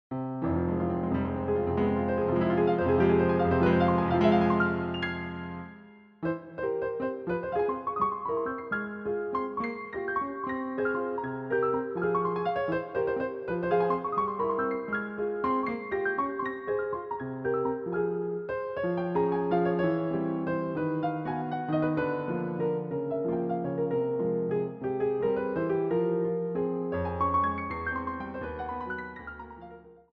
piano roll